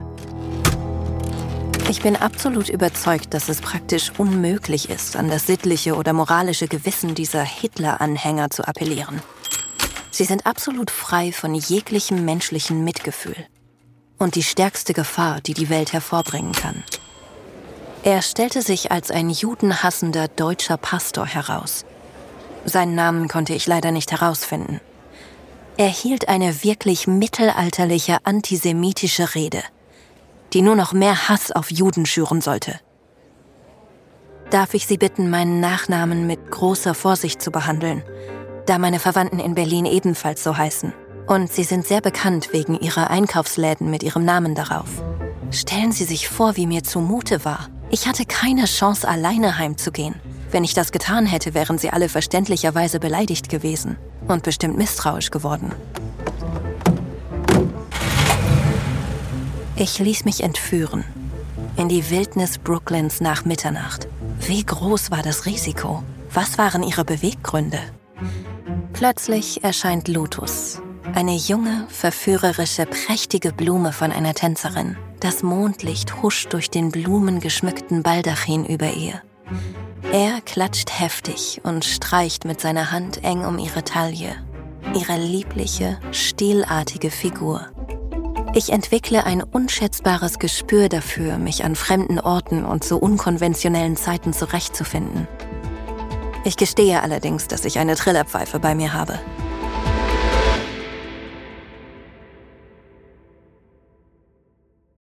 dunkel, sonor, souverän, markant, sehr variabel, plakativ, hell, fein, zart
Tutorial